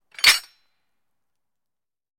Metal Animal Traps; Metal Animal Trap Closes With Light Creak And Ring Out. - Metal Trap